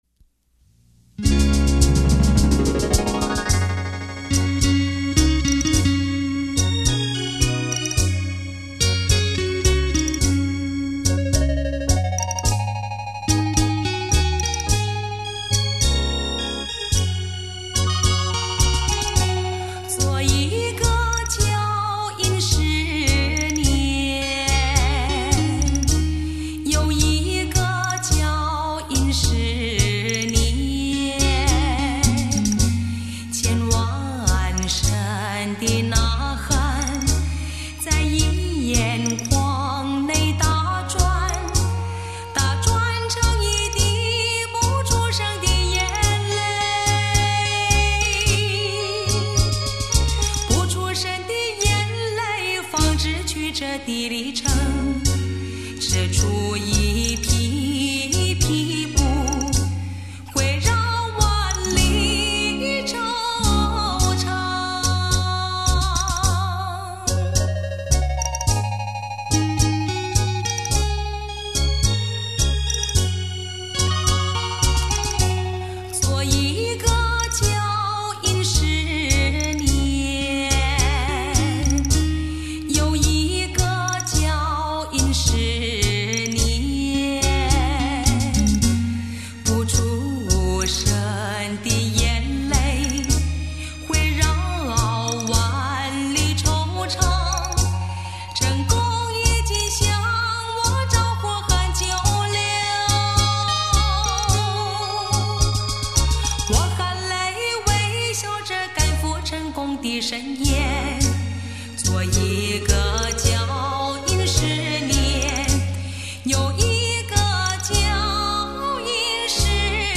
任何技法上的修饰，出于最自然的人声，配器虽只用了一台电子琴伴奏，
但是简约不简单，今天听来依然清新如春风拂面、甘露润田。